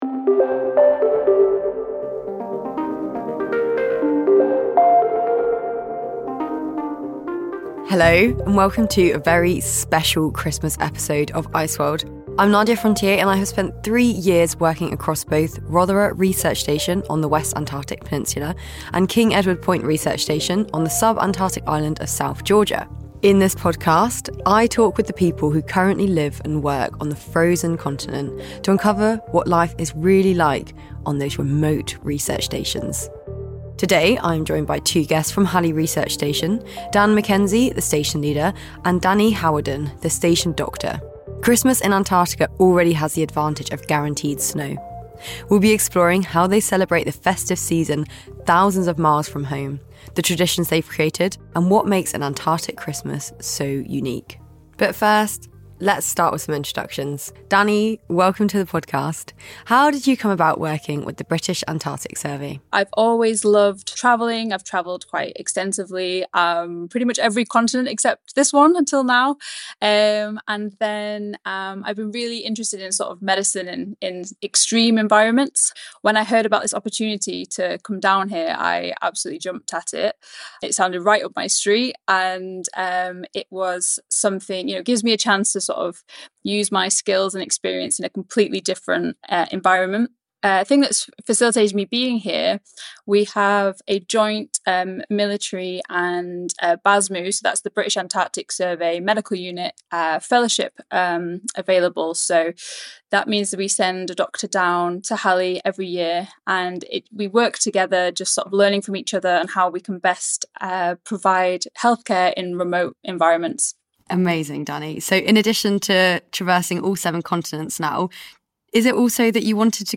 From polar scientists to plumbers, ICEWORLD is a series of interviews with ordinary people who are doing extraordinary jobs in Antarctica. The team talk climate science, extreme living, expeditions and becoming a community.